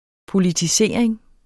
Udtale [ polidiˈseˀɐ̯eŋ ]